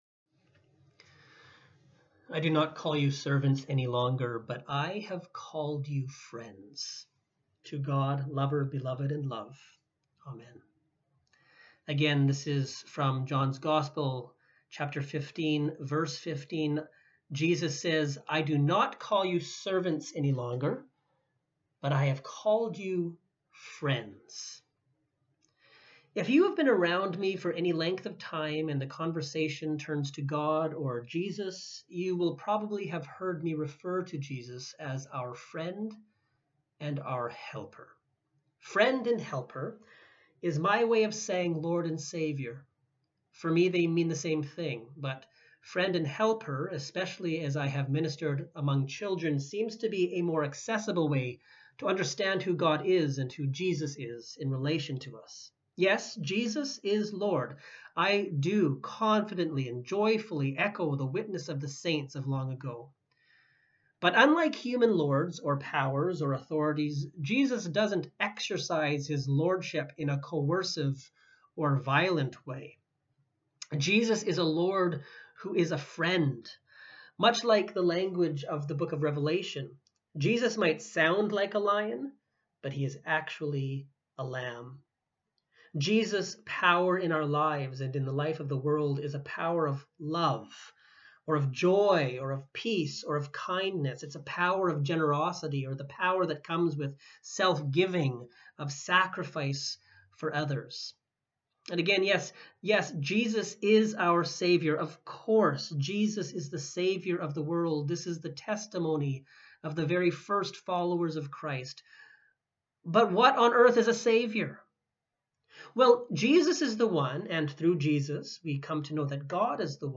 Sermons | St. Dunstan's Anglican
Guest Speaker